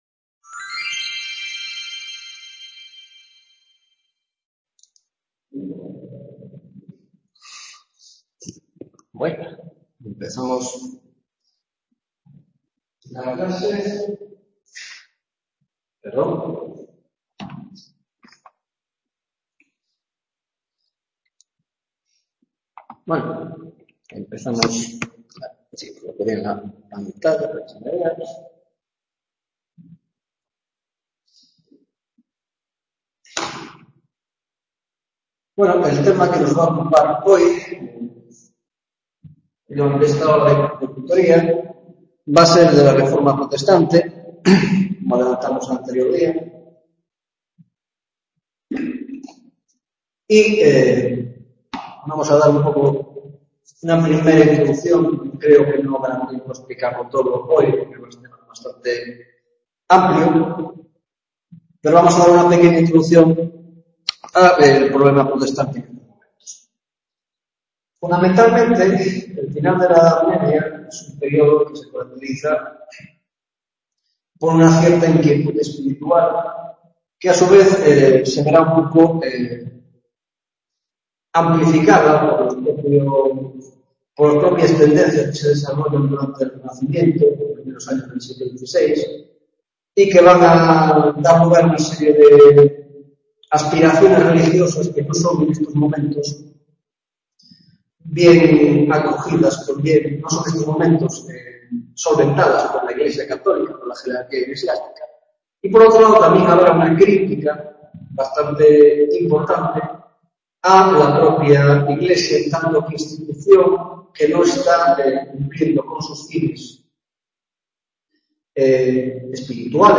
5ª Tutoría Historia Moderna, Historia del Arte - Reforma Protestante- Centro Asociado de A Coruña